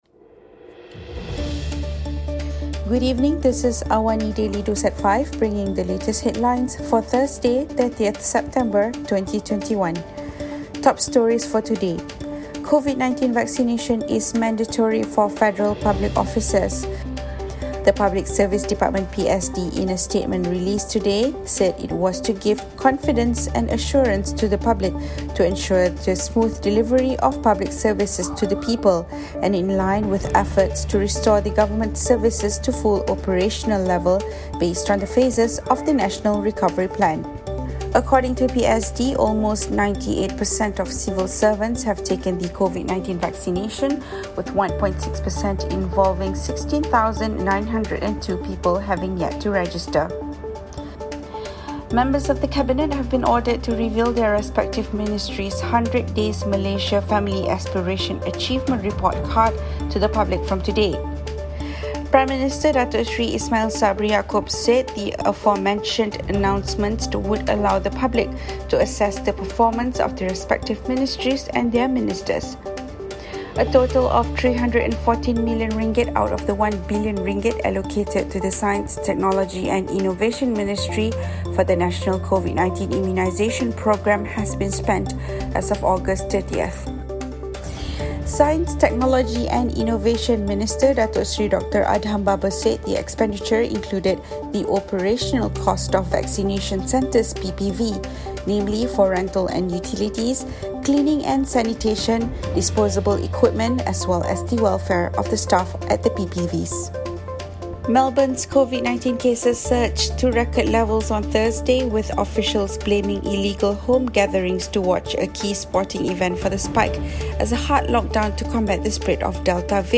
Listen to the top stories of the day, reporting from Astro AWANI newsroom — all in 3 minutes.